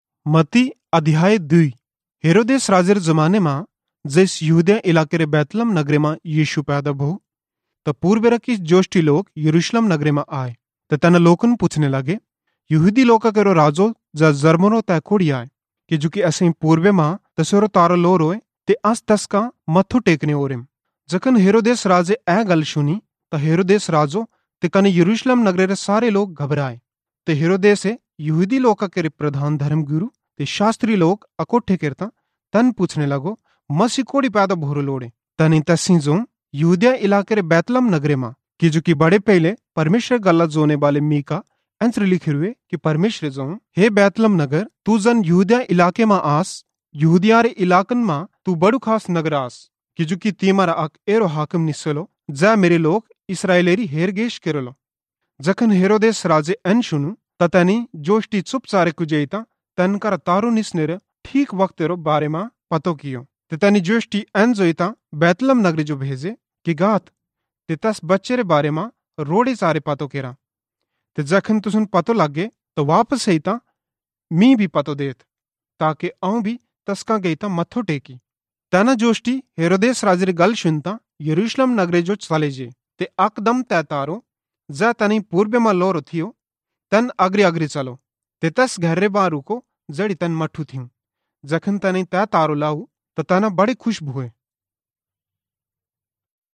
Badhrawahi Audio Bible (WORKS IN PROGRESS)